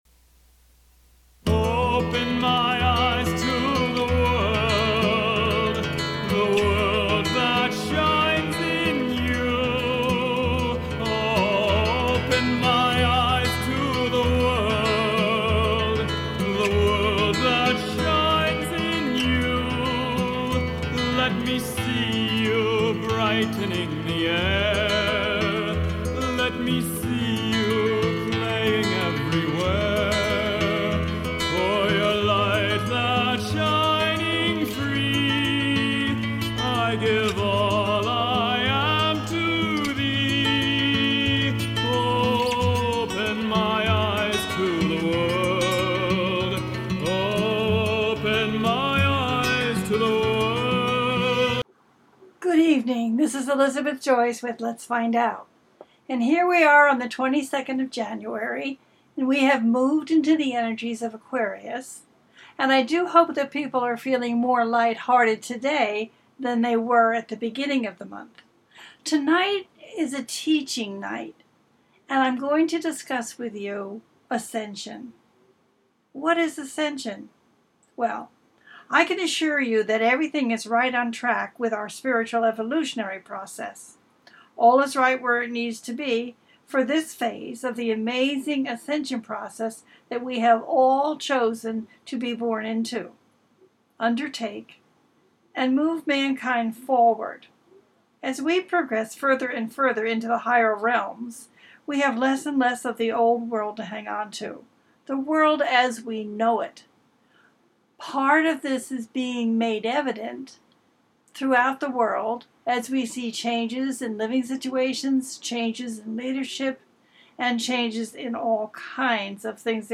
Talk Show
This show brings a series of fascinating interviews with experts in the field of metaphysics.
The listener can call in to ask a question on the air.
Each show ends with a guided meditation.